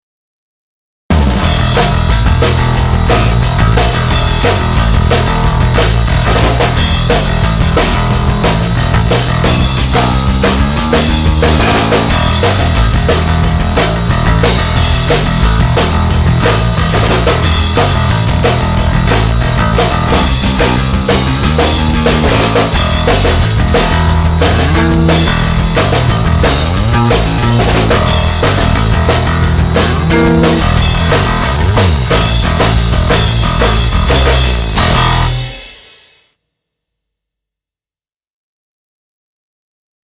Fretless sound, perfect for finger picking and use of rear pickup.
me-20b_patch_demo_4-2.mp3